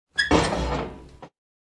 open-door.ogg.mp3